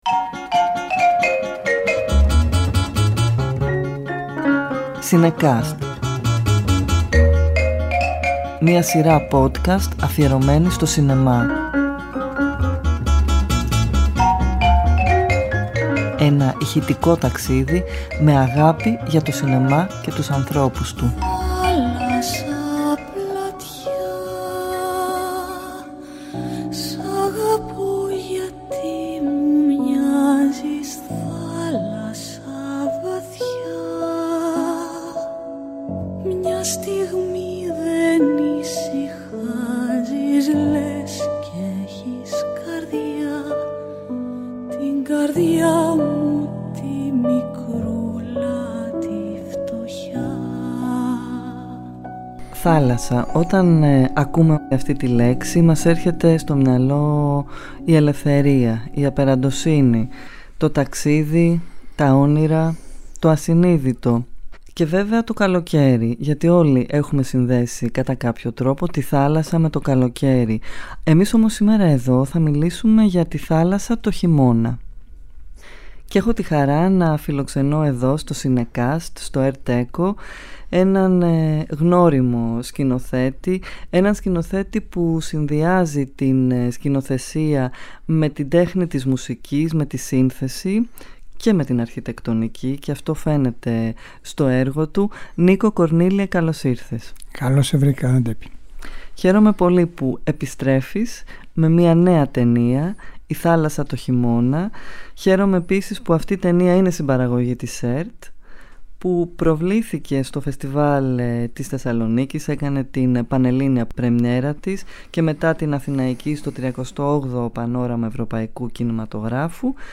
Κάθε επεισόδιο ζωντανεύει μια ταινία μέσα από συνεντεύξεις, αποσπάσματα και μουσικές.